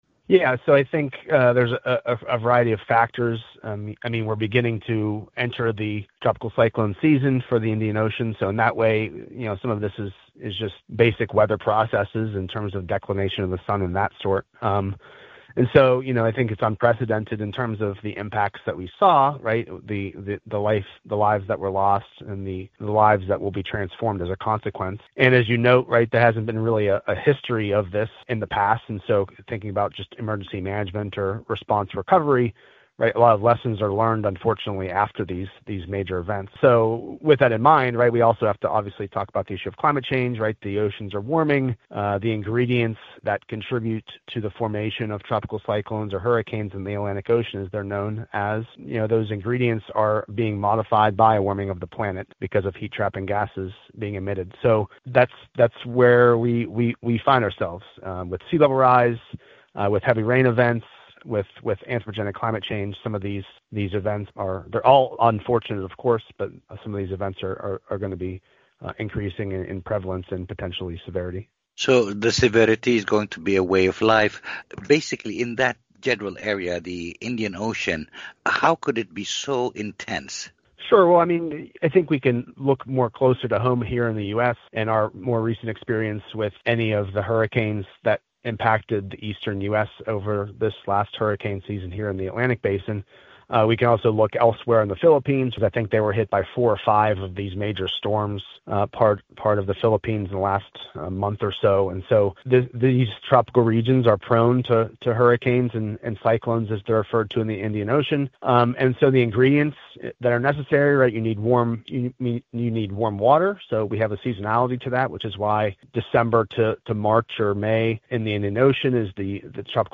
In a conversation